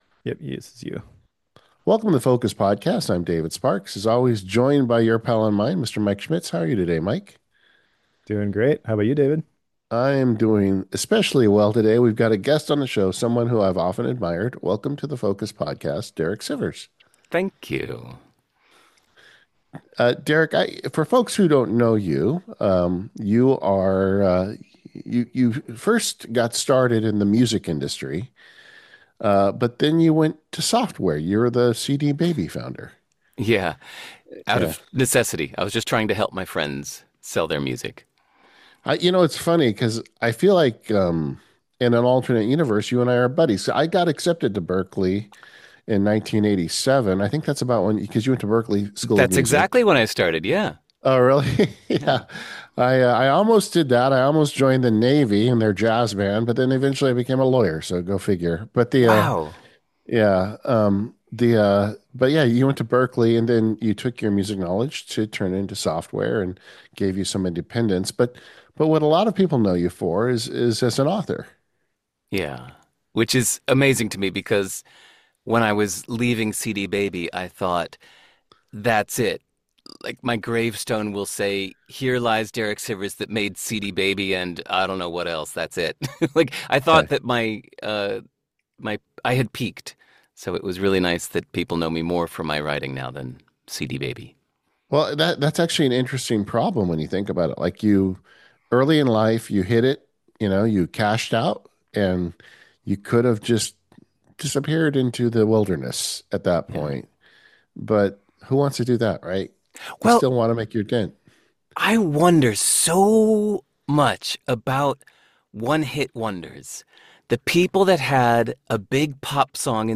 Interviews: